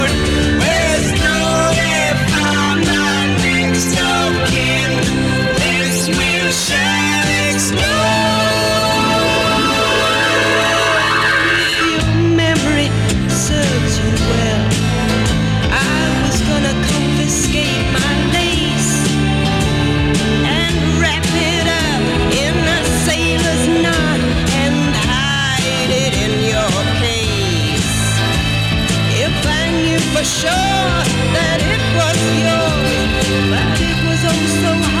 # Jazz